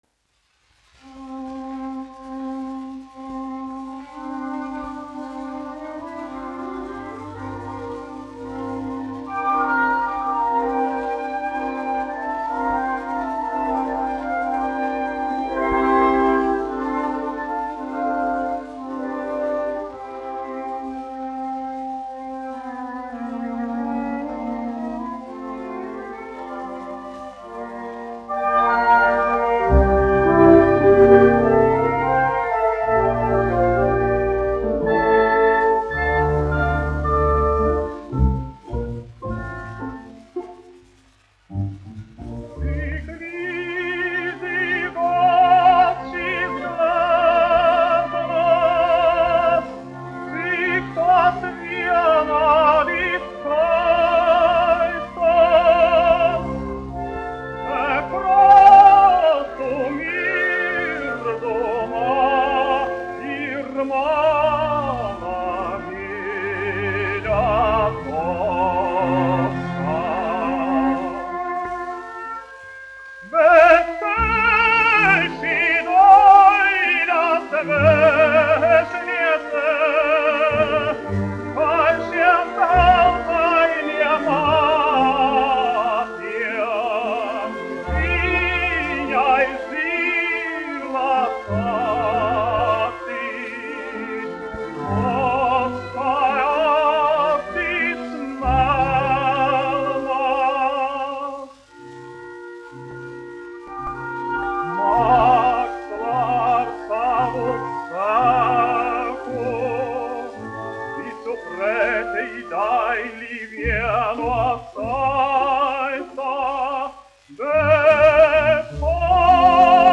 Latvijas Nacionālās operas orķestris, izpildītājs
1 skpl. : analogs, 78 apgr/min, mono ; 25 cm
Operas--Fragmenti
Latvijas vēsturiskie šellaka skaņuplašu ieraksti (Kolekcija)